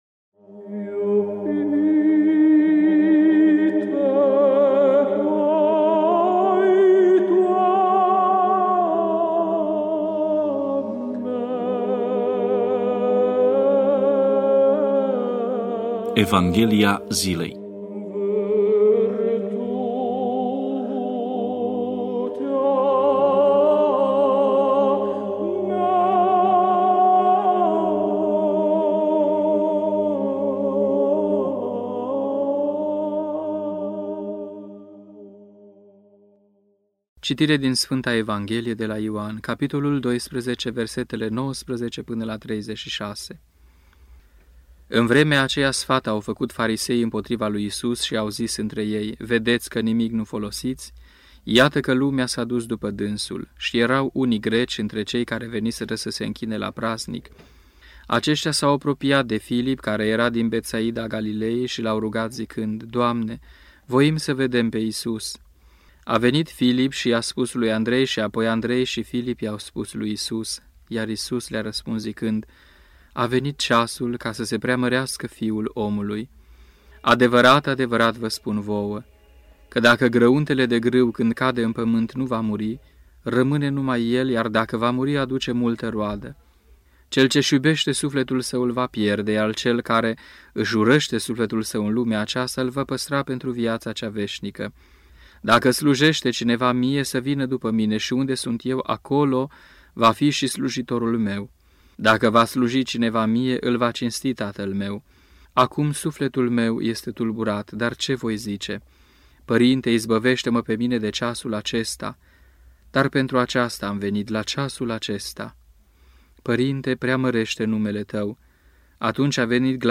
Meditație la Evanghelia zilei